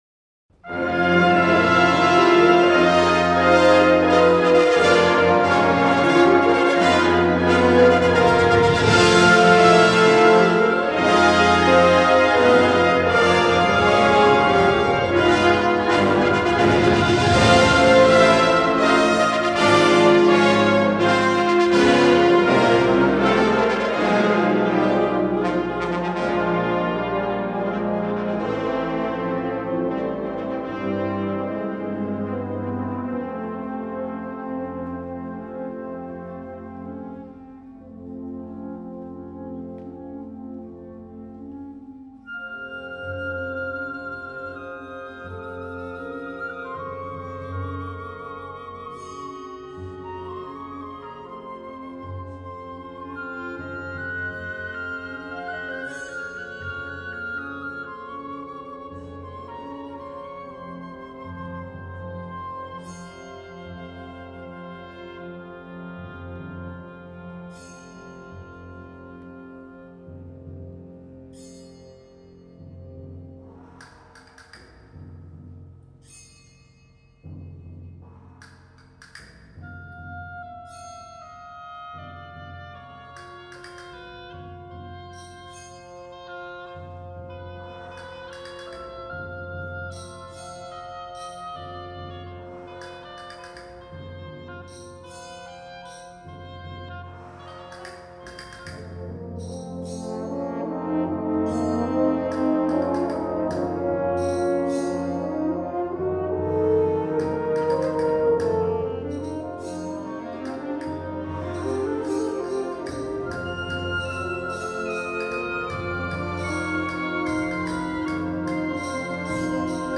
Gattung: Konzertwerk